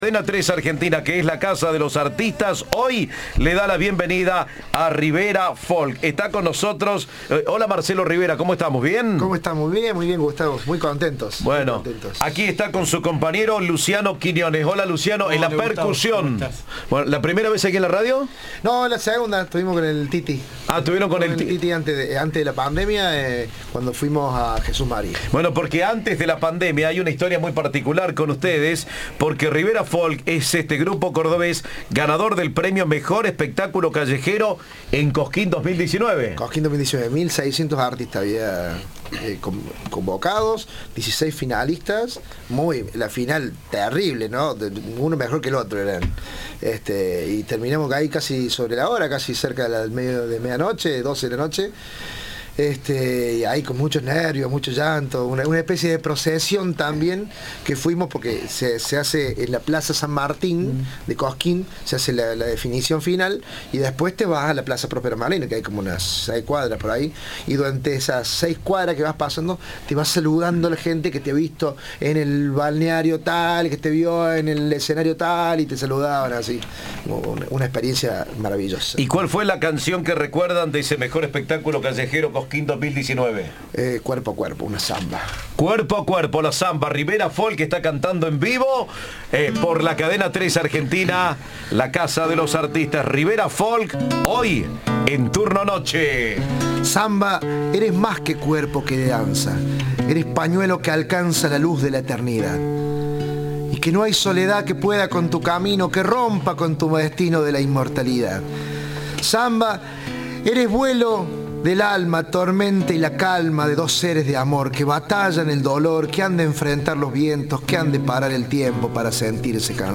El grupo cordobés se presenta el domingo 19. En 2019, ganaron en Cosquín el premio al Mejor Espectáculo Callejero y se llevaron en Jesús María la Mención de Honor.